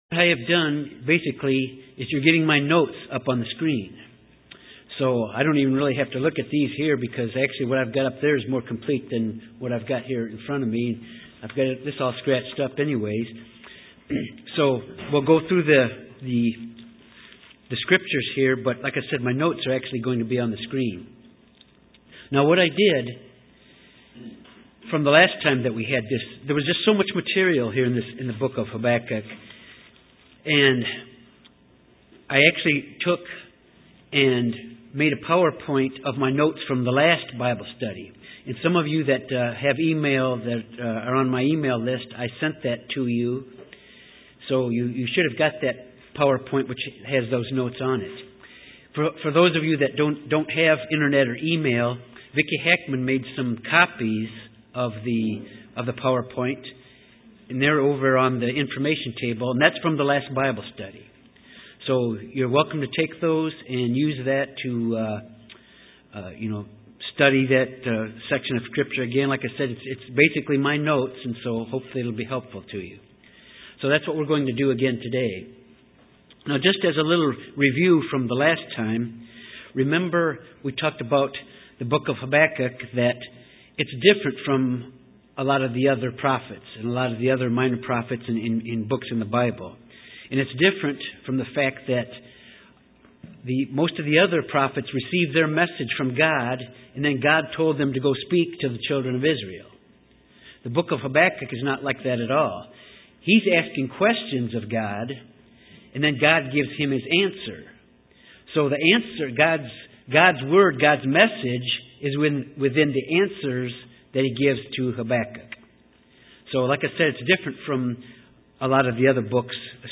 Bible study on Habakkuk 2 and 3. Chapter 2 focuses on God's answer to Habakkuk about why He is going to use the Babylonians to judge Judah. Chapter 3 focuses on Habakkuk's prayer to God, filled with wonder and awe at God's might and strength, and waiting for God to punish the Babylonians in turn.